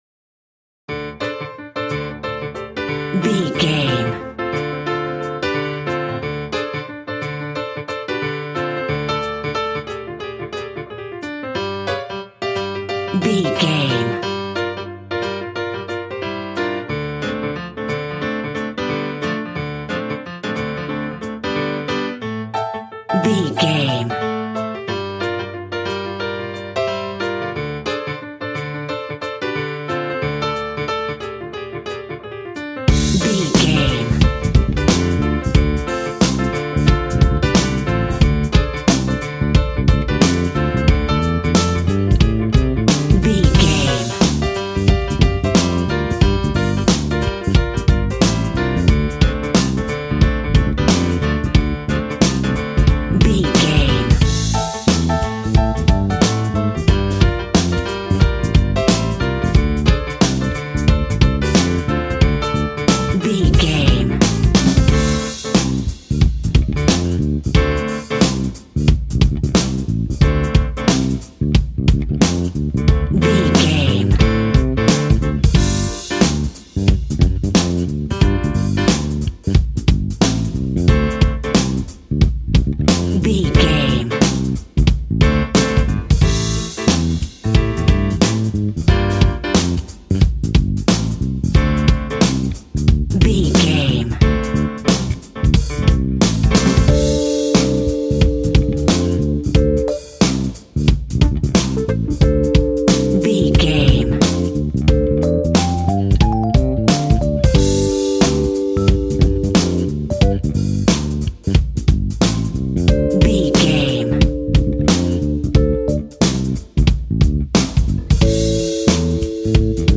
Aeolian/Minor
sad
mournful
bass guitar
electric guitar
electric organ
drums